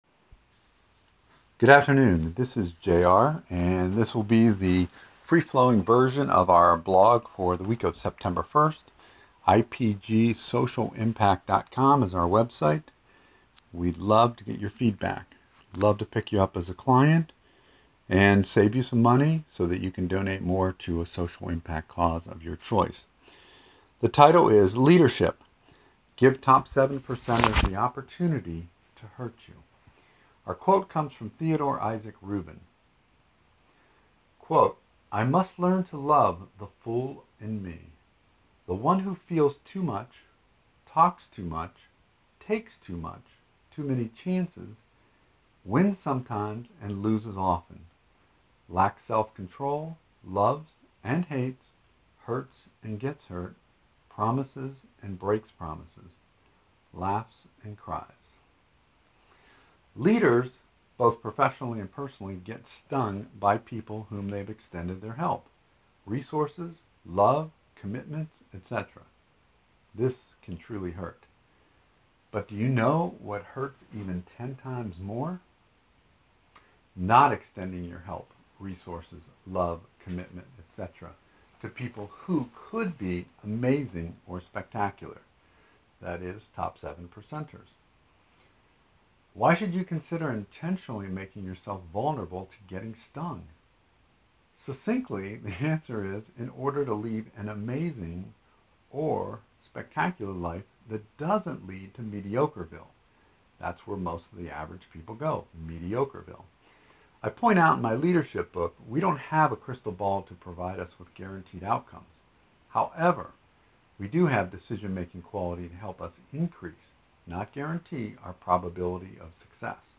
Just click the “Play Arrow” below to listen to the free-flowing audio version of this blog.